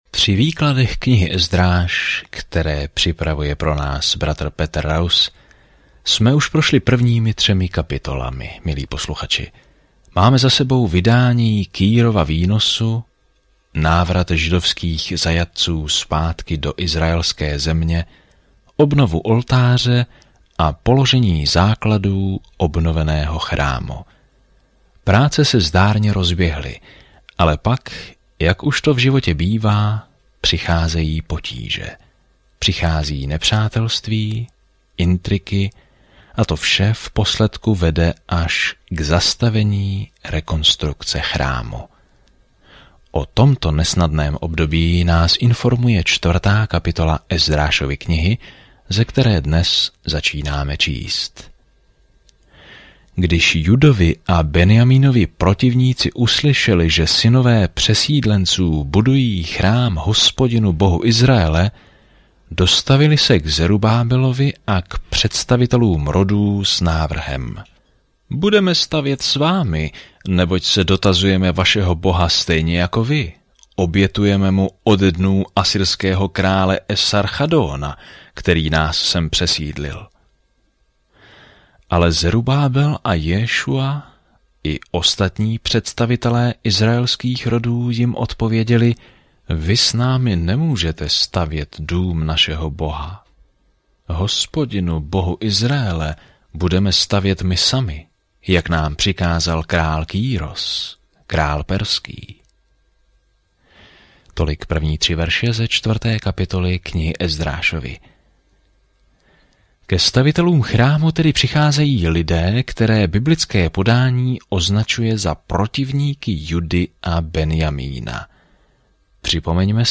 Písmo Ezdráš 4 Den 5 Začít tento plán Den 7 O tomto plánu Izraelci, kteří se vrátili ze zajetí, znovu postavili chrám v Jeruzalémě a písař jménem Ezra je učí, jak znovu poslouchat Boží zákony. Denně procházejte Ezdrášem a poslouchejte audiostudii a čtěte vybrané verše z Božího slova.